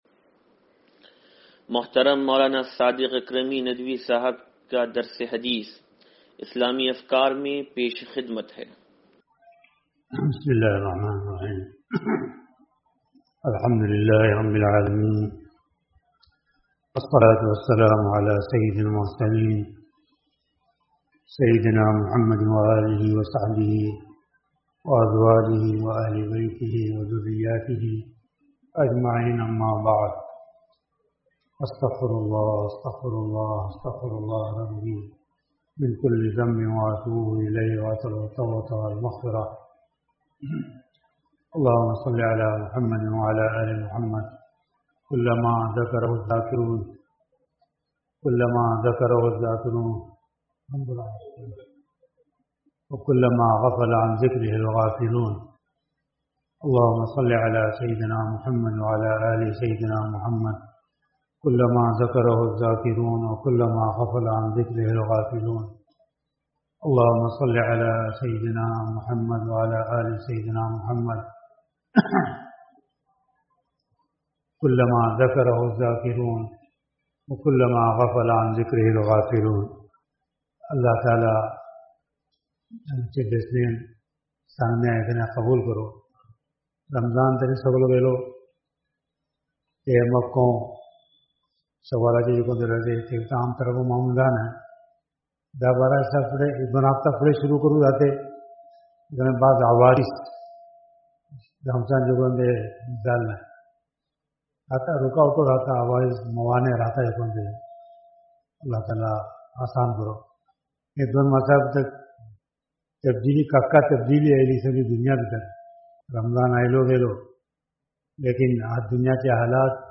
درس حدیث نمبر 0636